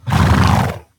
snort.ogg